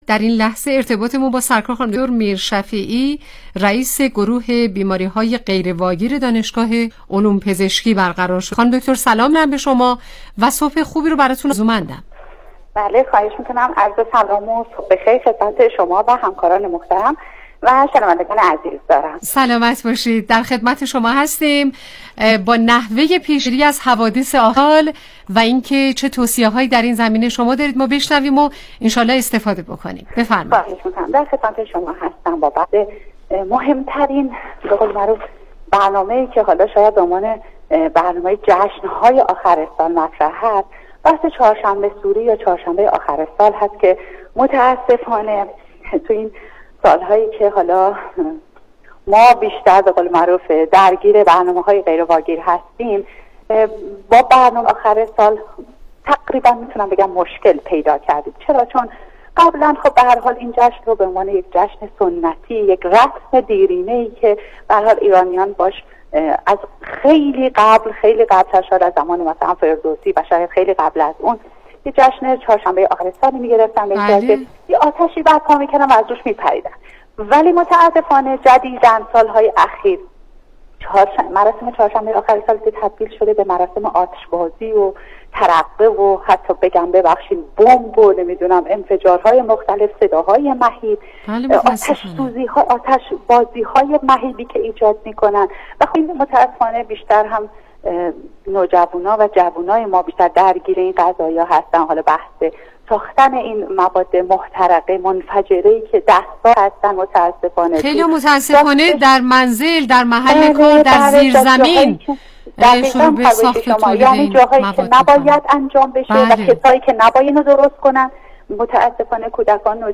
برنامه رادیویی کانون مهر&nbsp